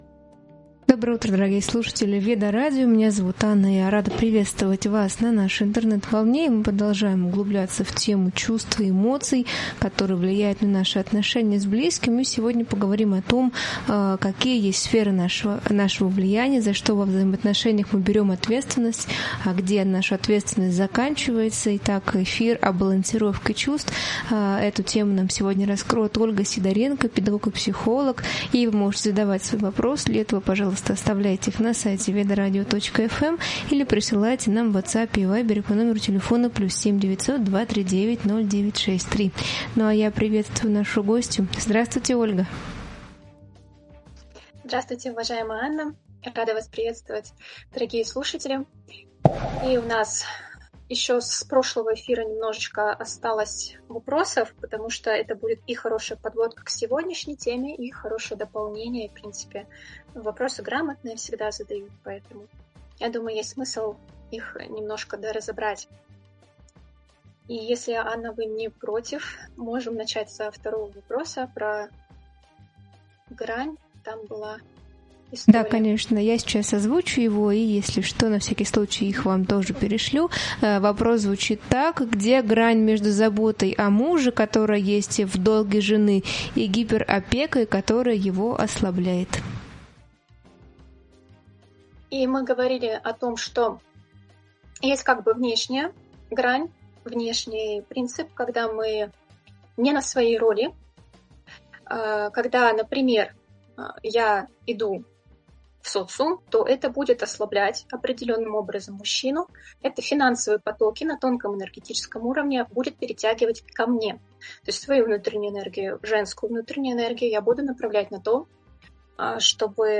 В этом эфире обсуждаем, как чувства и эмоции влияют на отношения, и как важно осознавать собственную ответственность за них. Разбираем грани заботы и гиперопеки, влияние кармы и прошлого на поведение, личные границы, алгоритмы построения отношений и работу с обидами.